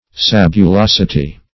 Search Result for " sabulosity" : The Collaborative International Dictionary of English v.0.48: Sabulosity \Sab`u*los"i*ty\, n. The quality of being sabulous; sandiness; grittiness.